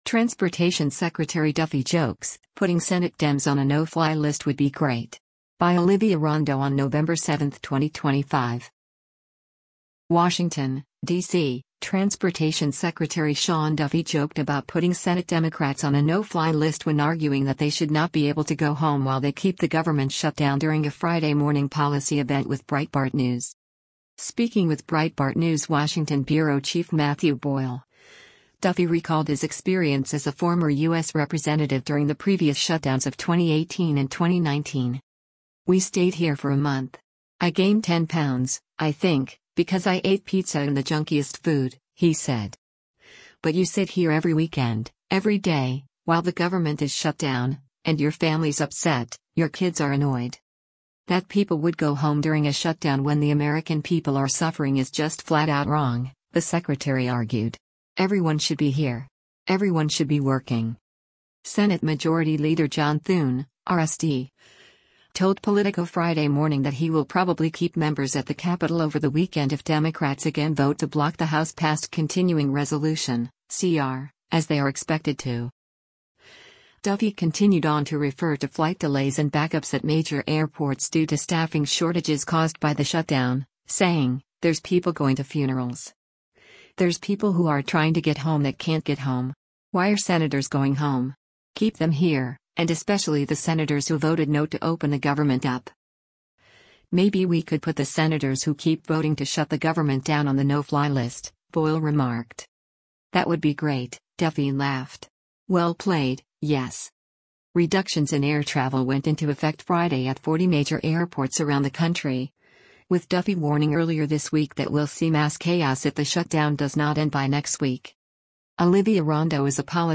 WASHINGTON, DC — Transportation Secretary Sean Duffy joked about putting Senate Democrats on a “no-fly list” when arguing that they should not be able to go home while they keep the government shut down during a Friday morning policy event with Breitbart News.
“That would be great,” Duffy laughed.